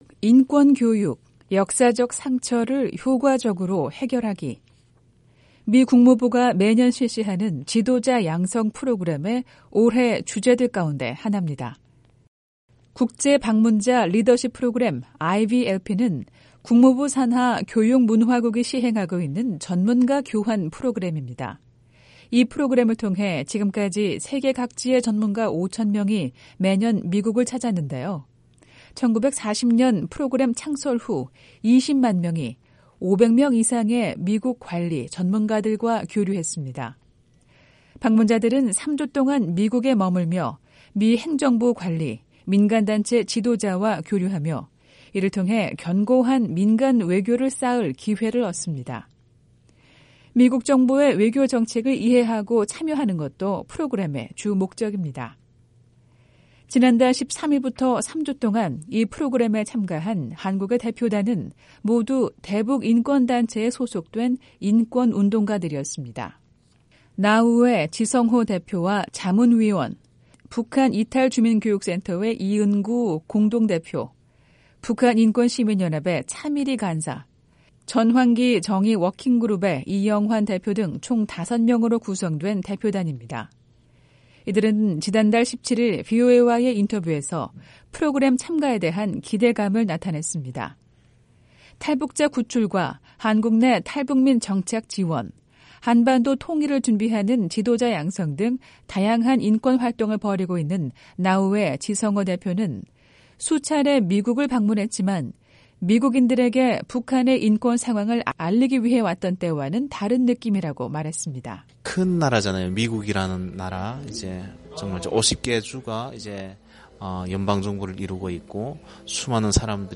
생생 라디오 매거진, 한 주 간 북한 관련 화제성 뉴스를 전해 드리는 ‘뉴스 풍경’ 입니다. 미 국무부가 주관하는 지도자 연수 프로그램에 한국의 대북인권단체들이 참가했습니다.